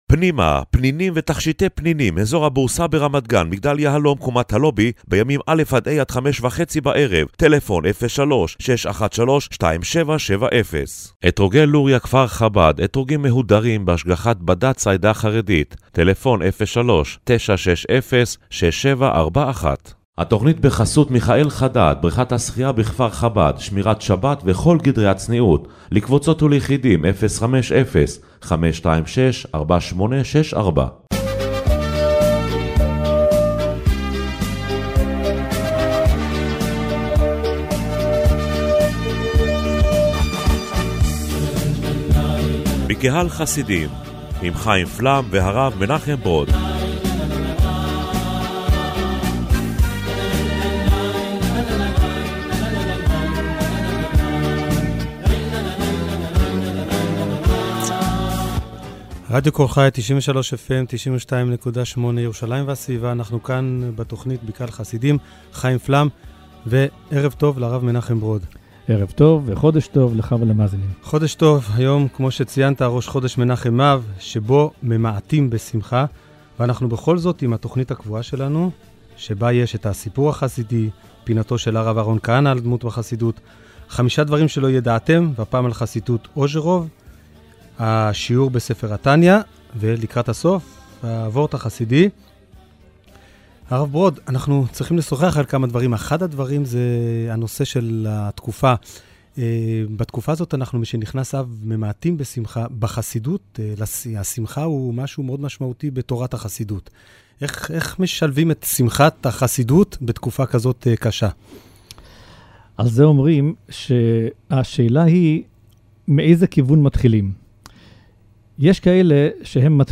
במרכז תכנית הרדיו השבועית 'בקהל חסידים' השבוע עמדה ההתייחסות של החסידות לנושא 'ממעטין בשמחה', והאם אפשר לעבוד את ה' בלי שמחה? בפינה 'הסיפור החסידי ומה שמאחוריו' הובא סיפור על שני הצדיקים שהשלימו בעקבות סיפור על מפגש אנוסים.